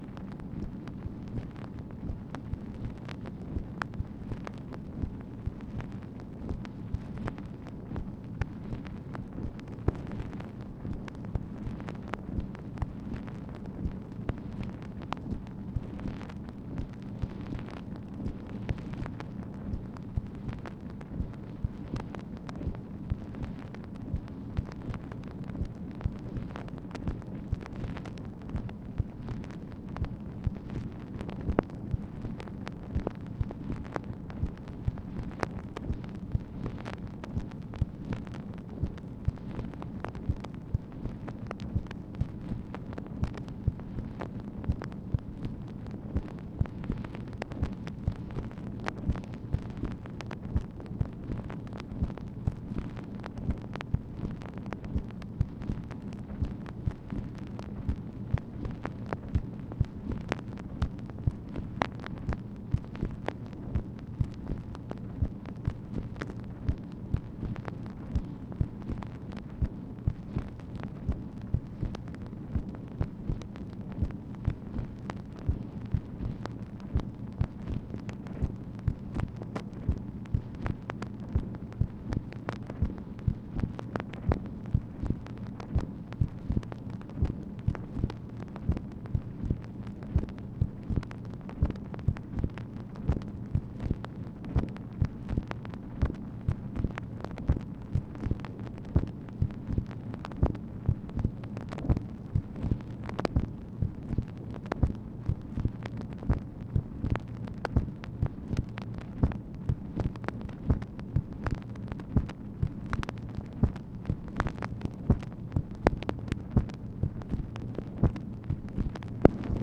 MACHINE NOISE, April 20, 1966
Secret White House Tapes | Lyndon B. Johnson Presidency